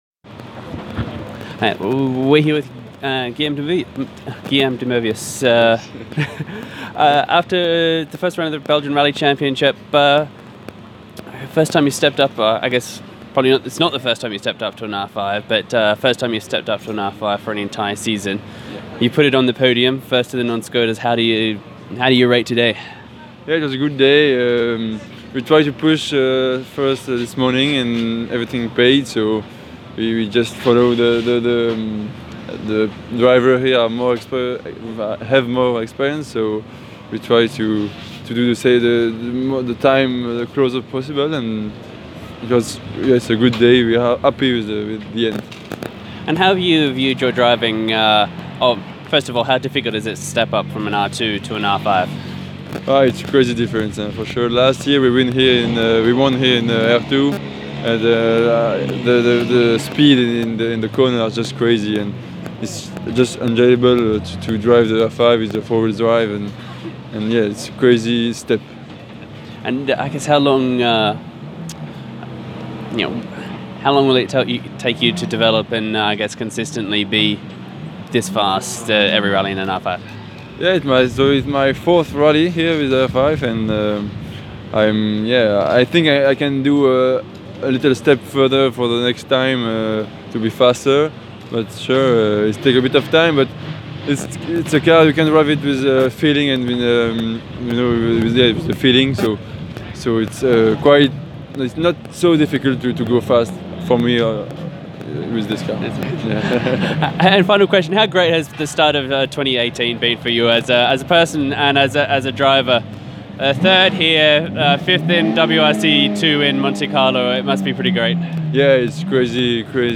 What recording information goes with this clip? Our exclusive interview and galleries brings you the views from the track, from the racers.